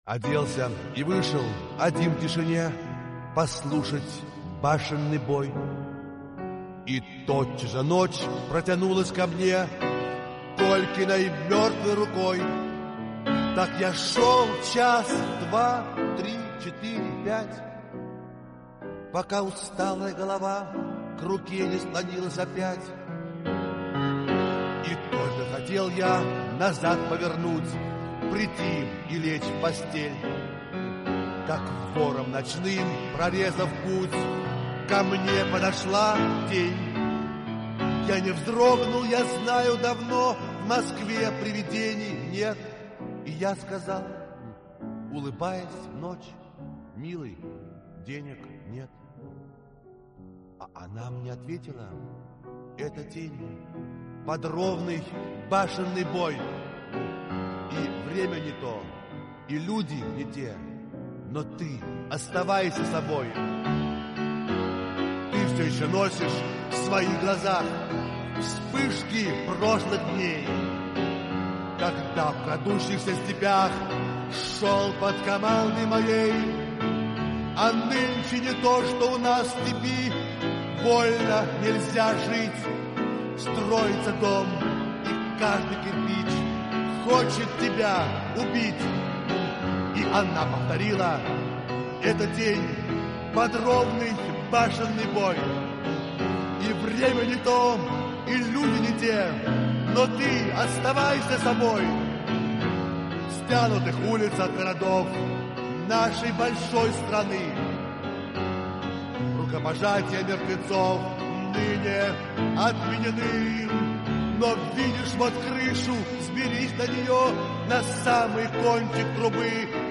Исполняет автор